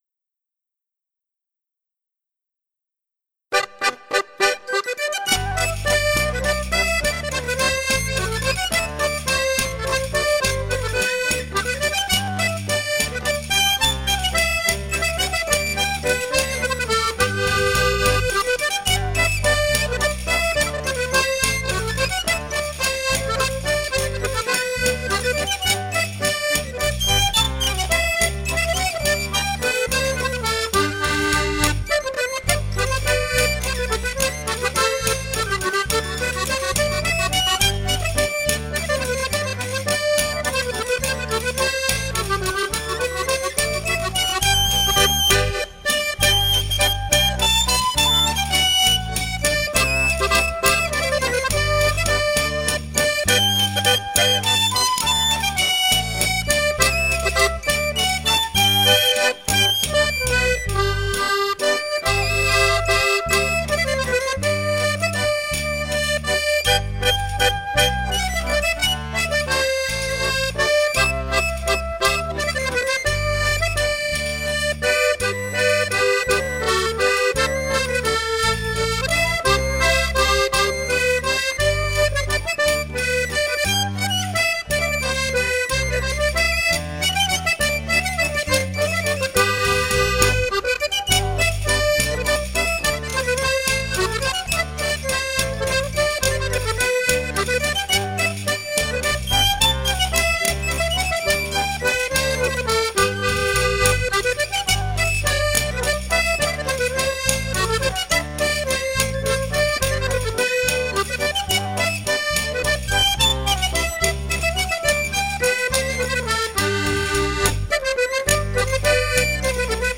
jota-de-tafalla-ralentie-13.mp3